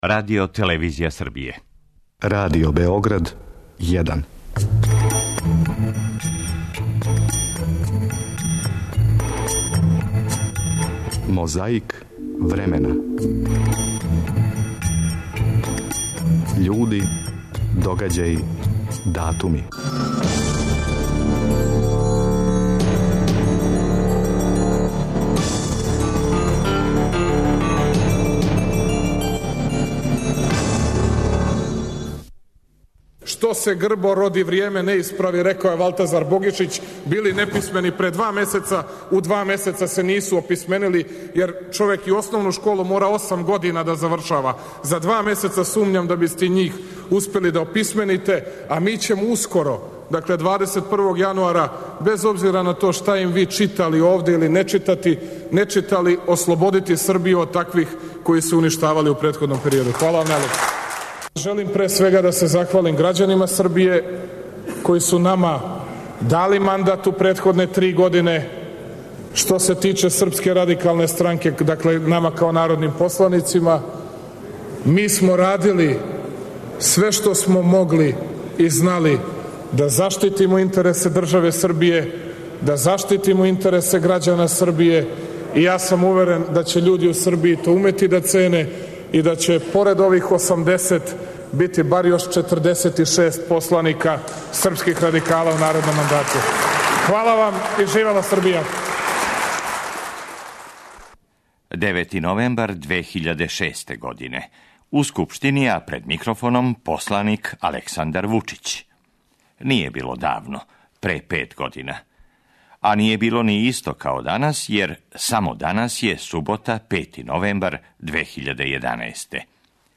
У још једној борби против пилећег памћења враћамо се у 2006. годину - 9. новембра у Скупштини, а пред микрофоном, говорио је посланик Александар Вучић.
Звучна коцкица са питањима Славка Ћурувије и Александра Тијанића.
Подсећа на прошлост (културну, историјску, политичку, спортску и сваку другу) уз помоћ материјала из Тонског архива, Документације и библиотеке Радио Београда. Свака коцкица Мозаика је један датум из прошлости.